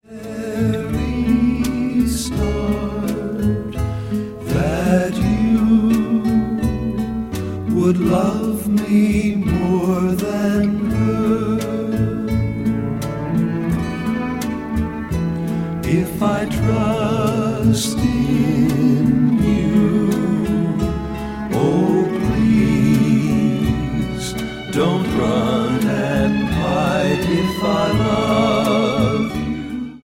Smooth, Easy Listening!
By Genre Easy Listening